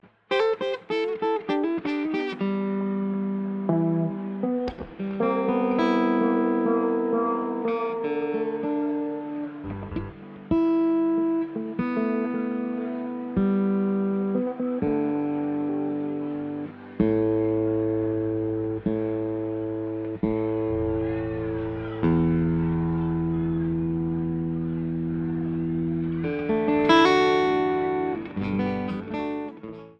Professionally recorded April 10, 2014 at the Wanee Music Festival in Live Oak, FL.Disc 1
Tuning (0:39)